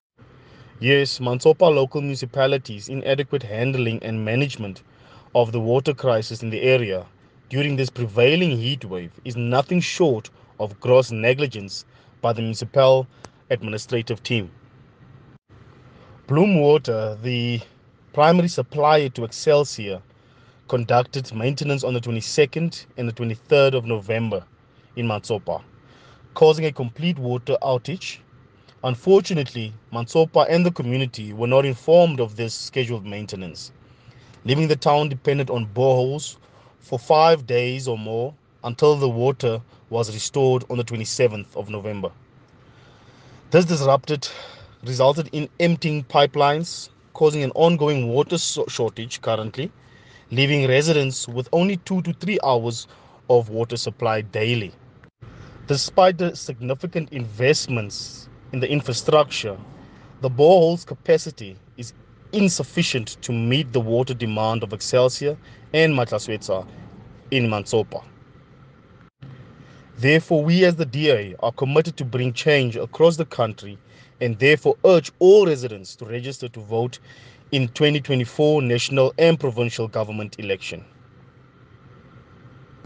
English soundbite by Cllr Lyle Bouwer,